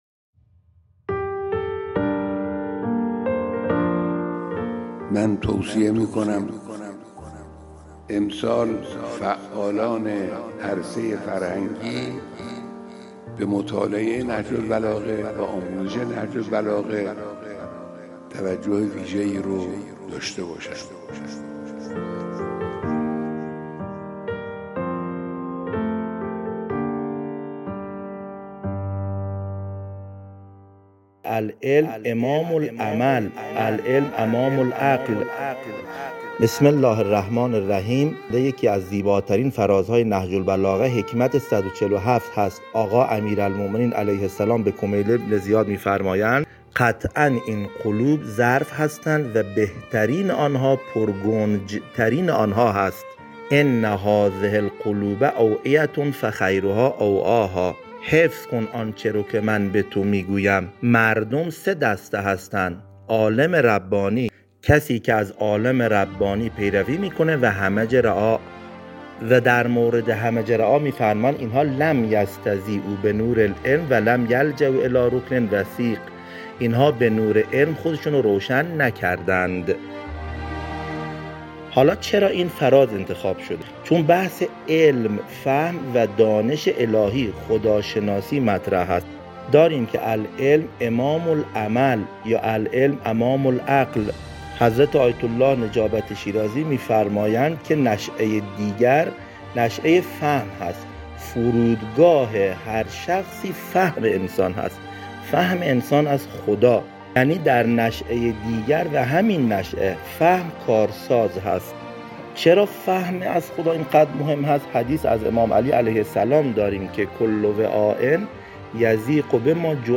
در جریان این پویش از دانشجویان، استادان و کارکنان خواسته شد که از هر نامه، کلام و خطبه‌های نهج‌البلاغه را که دوست دارند با صدا خود خوانده و ارسال کنند.